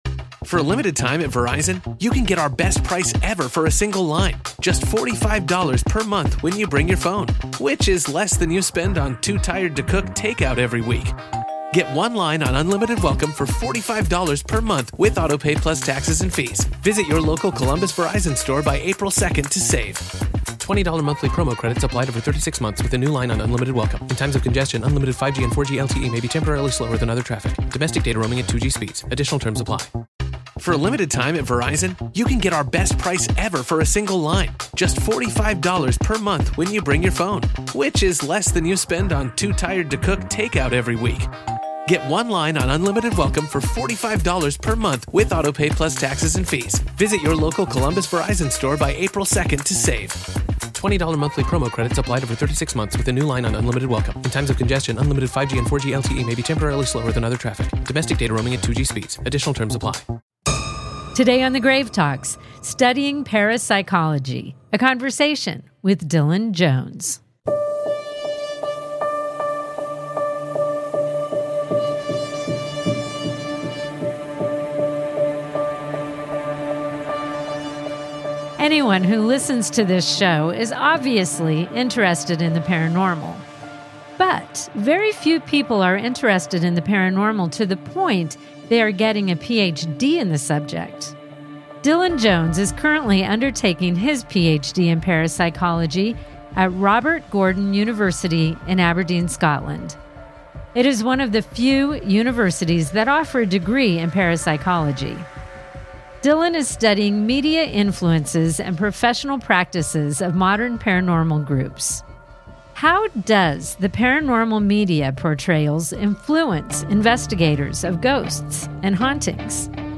a conversation about the field of parapsychology